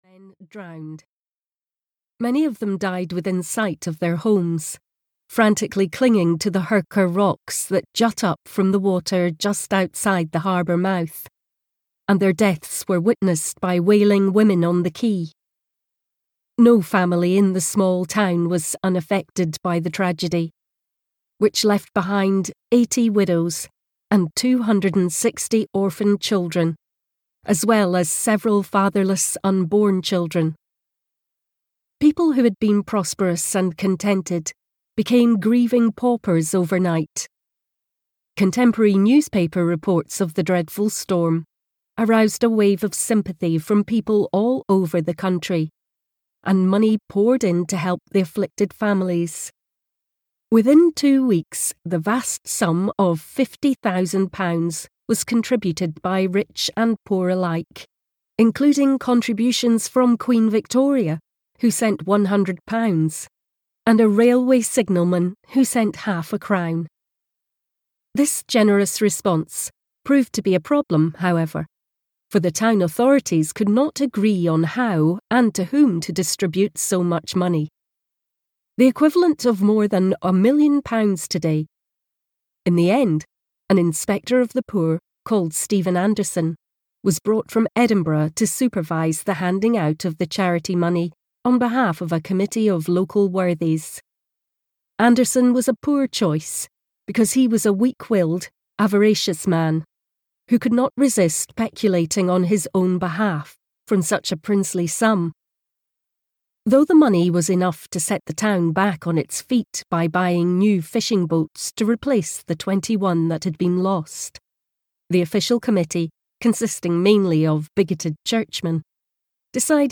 Turn of the Tide (EN) audiokniha
Ukázka z knihy